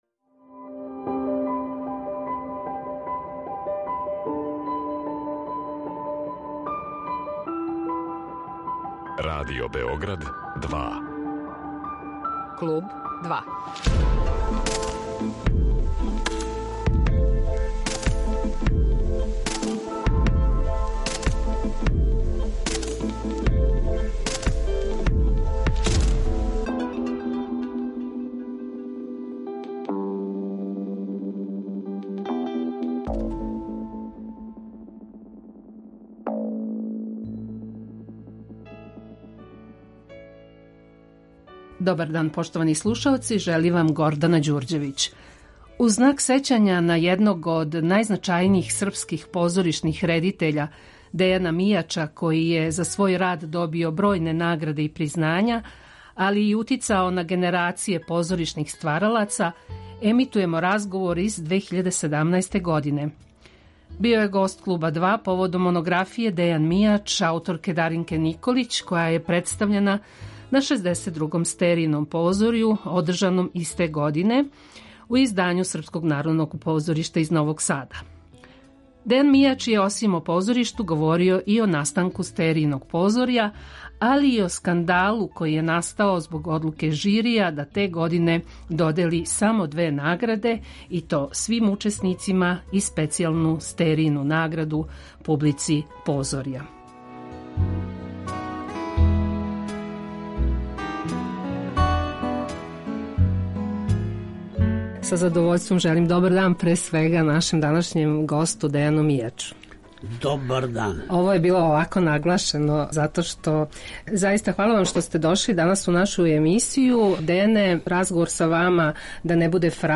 У знак сећања на једног од најзначајнијих српских позоришних редитеља Дејана Мијача, који је за свој рад добио бројне награде и признања, али и утицао на генерације позоришних стваралаца, емитујемо разговор из 2017. године.